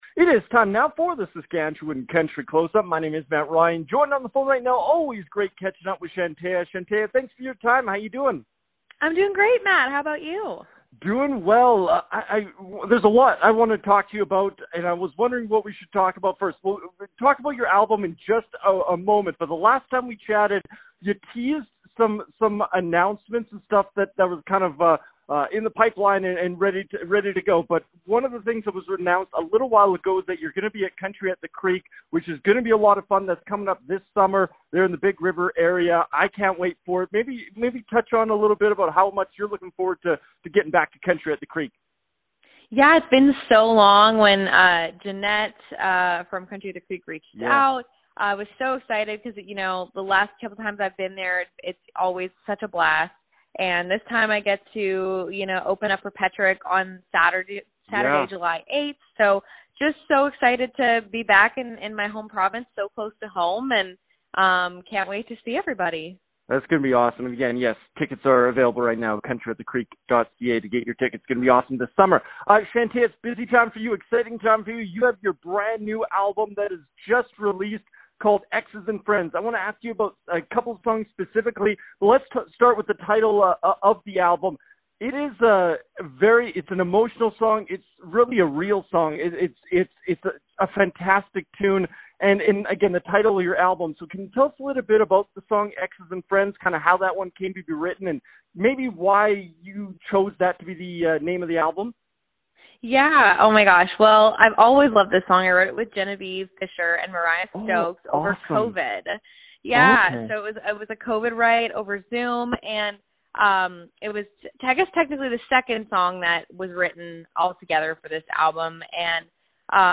joined us on the phone